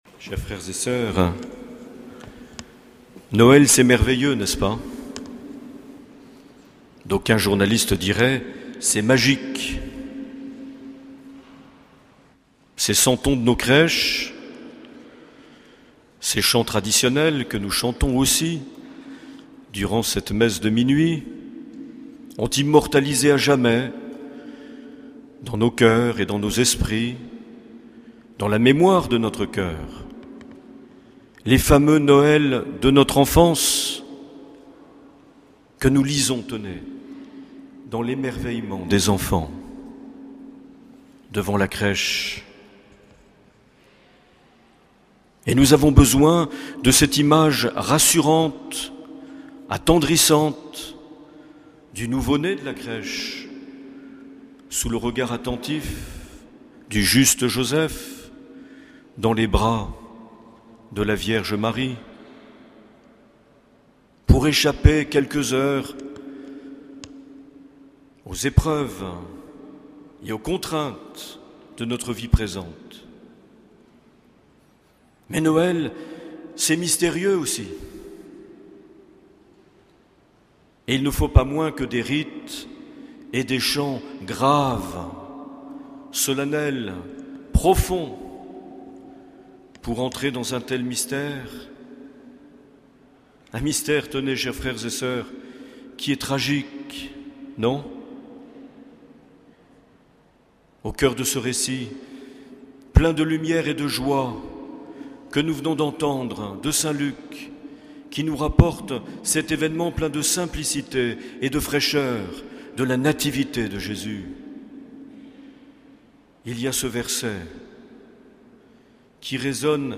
25 décembre 2010 - Bayonne cathédrale - Nuit de la Nativité du Seigneur
Une émission présentée par Monseigneur Marc Aillet